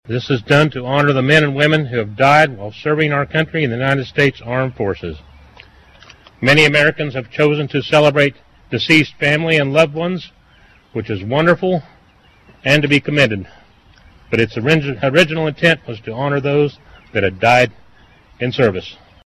Under a partly cloudy sky, Emporians gathered at the All Veterans Memorial to pay their respects to military service personnel who never made it home from combat as well as those who passed away over the last year.